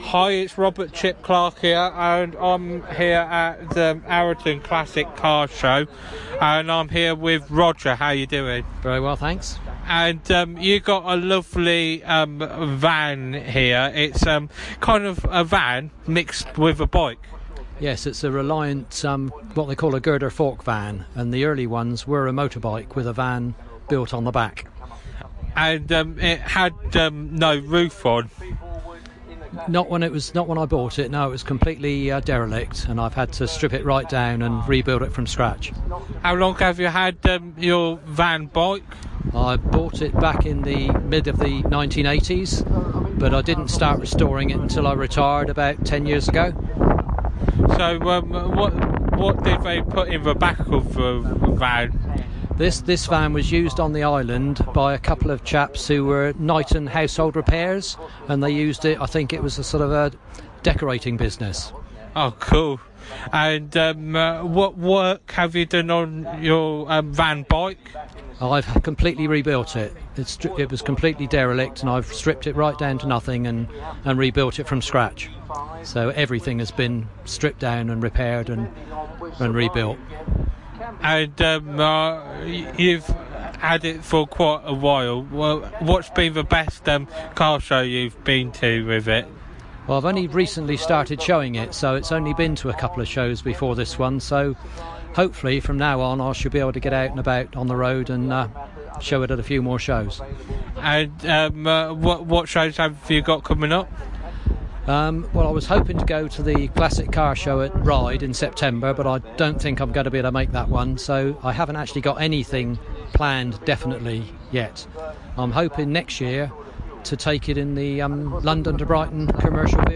Arreton Classic Car Show
Interview